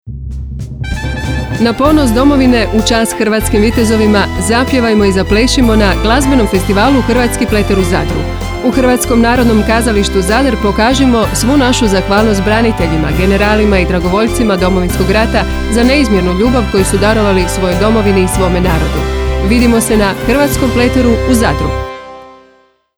DŽINGL